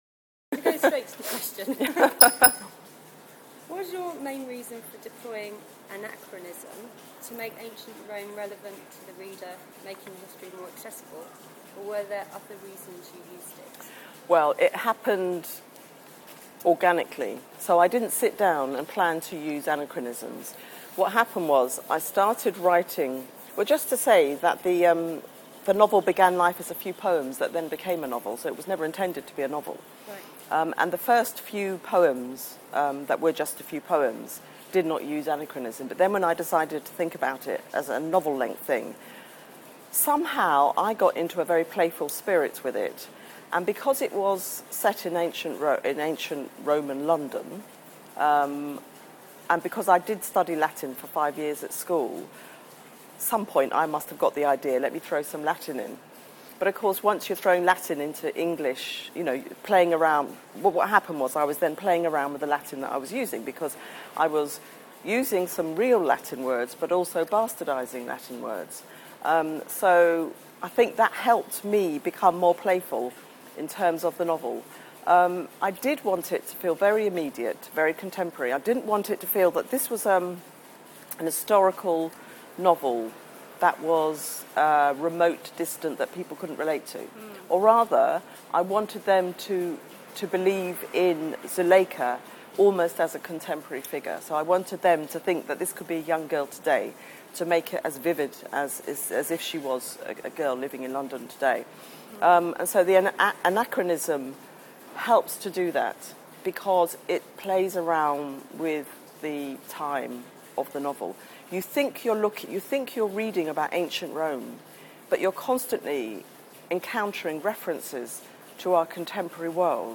Interview with the novelist Bernadine Evaristo